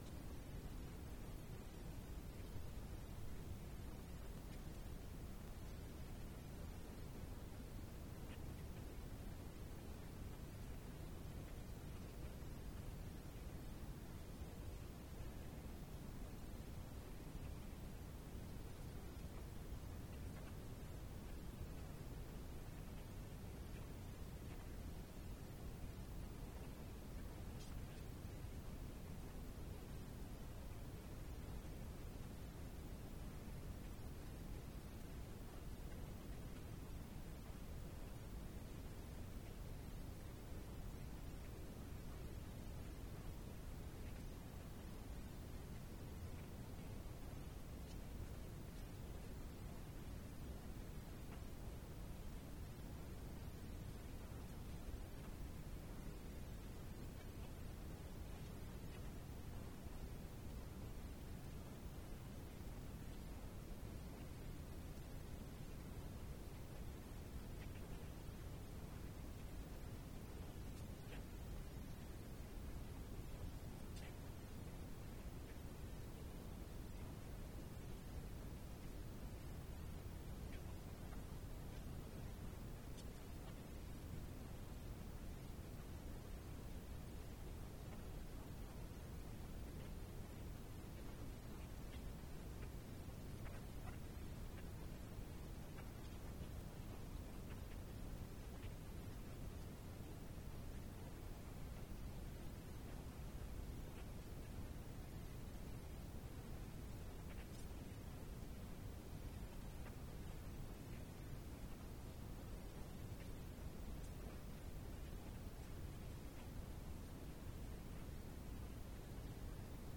Ecco le dichiarazioni raccolte nella giornata di presentazione del Report 2023: